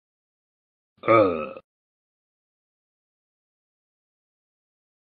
Talking Ben Ughh Meme Sound sound effects free download